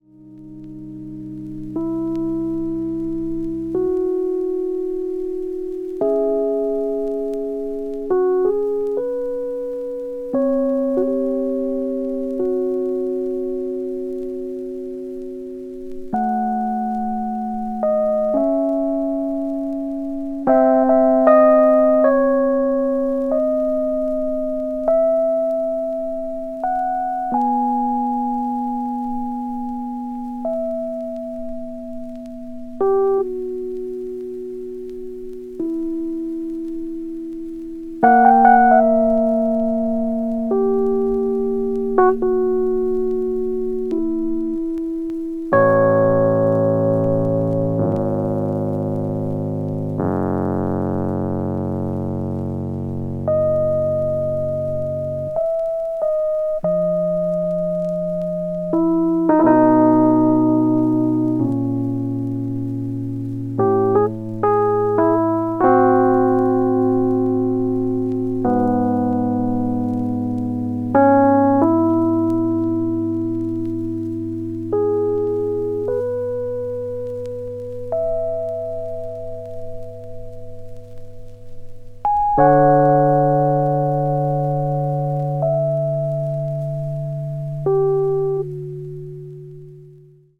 duo album